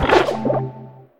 Cri de Fragroin mâle dans Pokémon HOME.
Cri_0916_♂_HOME.ogg